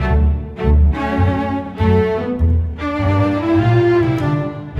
Rock-Pop 10 Strings 02.wav